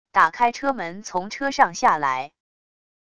打开车门从车上下来wav音频